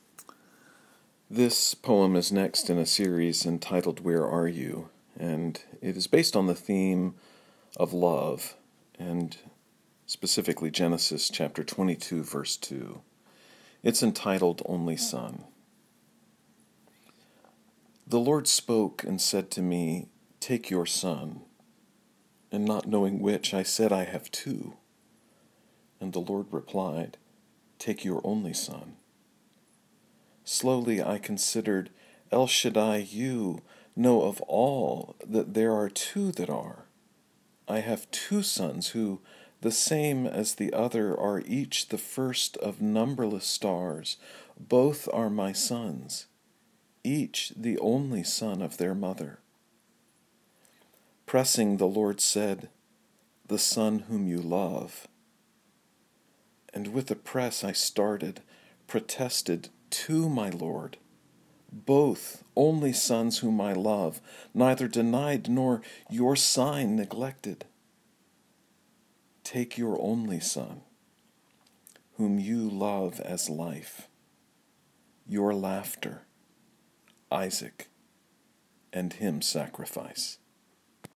If helpful, you may listen to me read the sonnet via the player below.